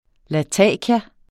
Udtale [ laˈtæˀkja ]